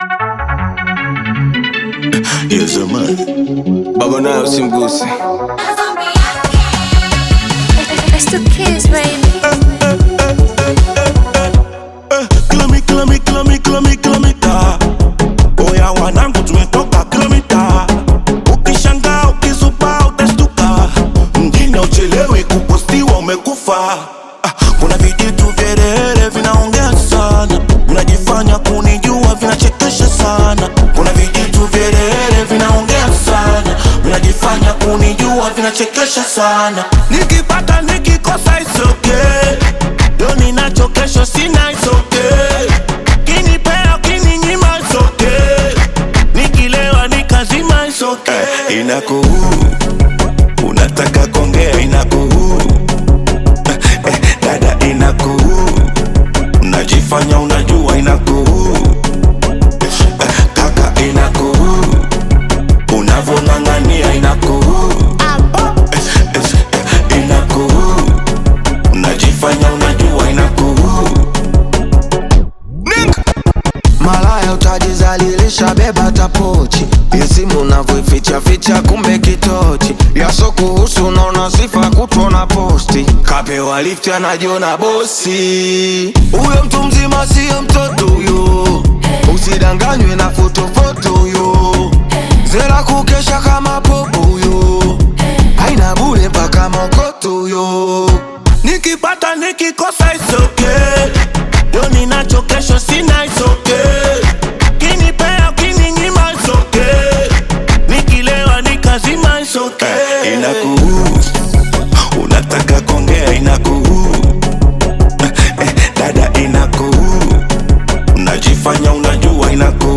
is a vibrant Afro-beat single